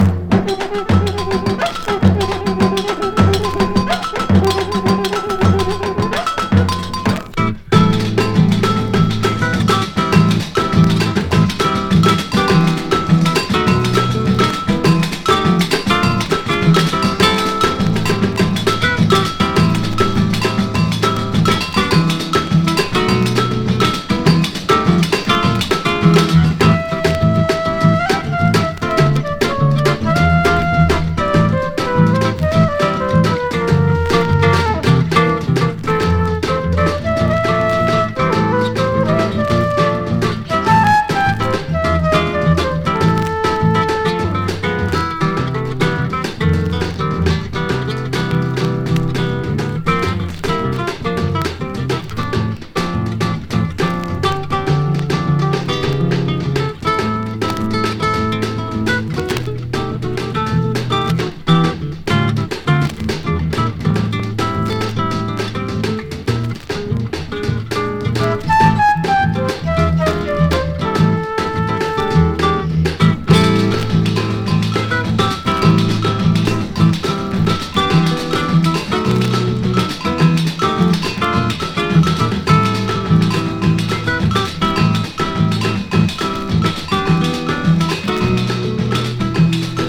EASY LISTENING / OST / FUNK / SOUL
現場には11万人もの観衆が押し寄せ、その歓声と熱狂が伝わってくる素晴らしいライヴ記録でもあります！